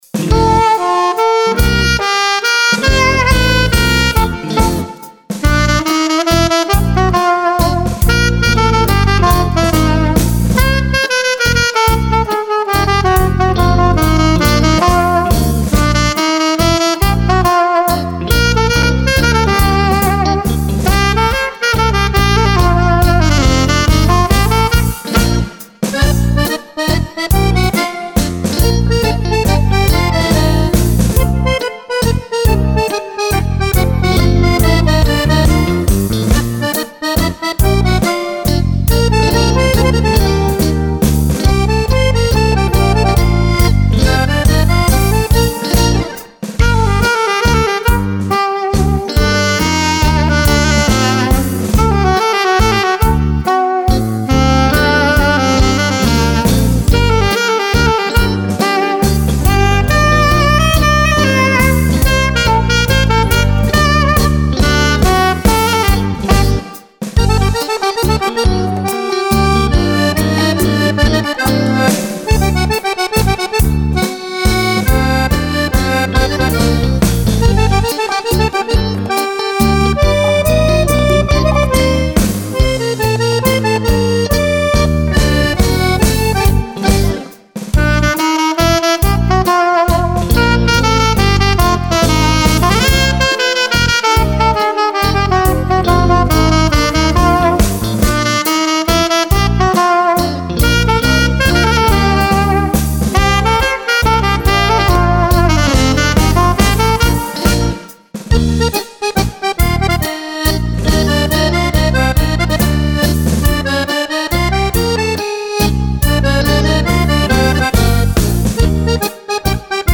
Mazurka
Brani ballabili per Fisarmonica e Sax